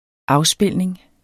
Udtale [ ˈɑwˌsbelˀneŋ ]